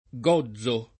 gozzo [ g0zz o ]